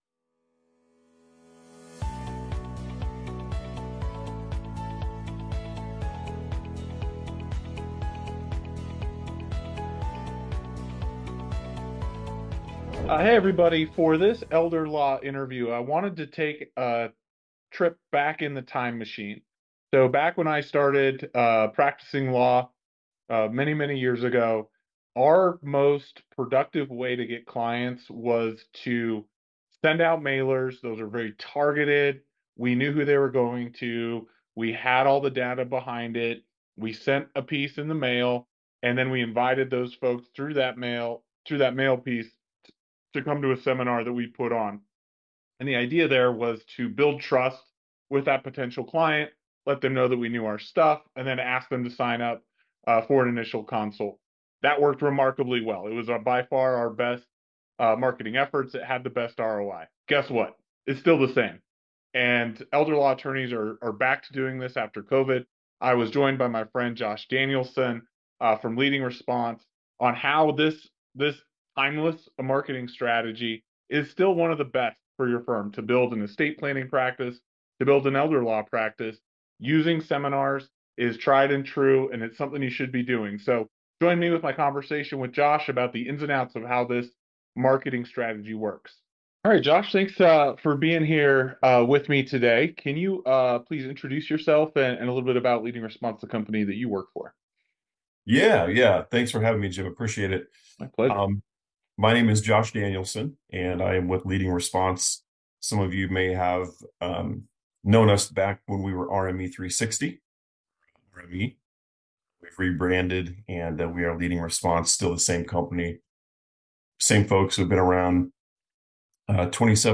In this Elder Law Interview